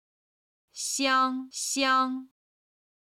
実際の音に起こすとこんな形になります。
香香(xiāng xiāng)